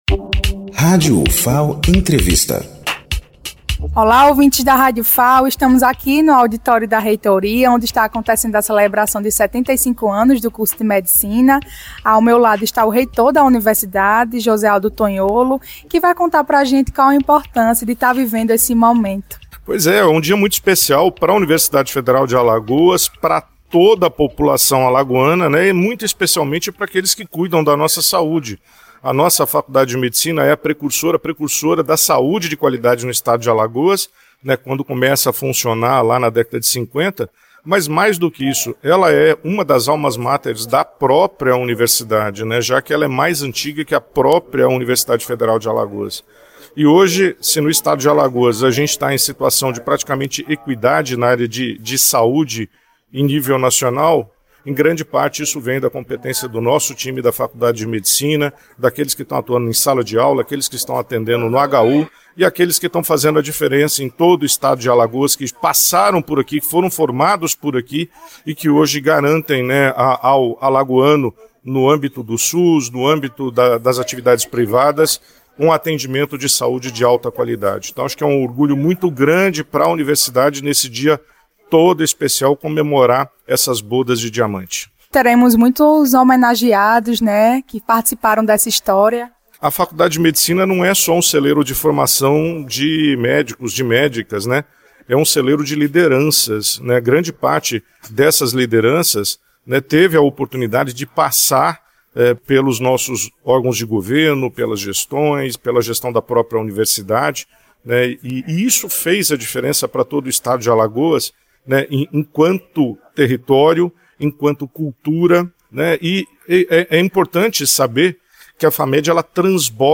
Entrevista com Josealdo Tonholo, reitor da Universidade Federal de Alagoas
Direto da cerimônia que marcou essa trajetória, conversamos com o reitor Josealdo Tonholo, que compartilha a emoção de ver a mais antiga unidade acadêmica da Ufal completar mais de sete décadas de contribuições para a saúde e a educação em Alagoas.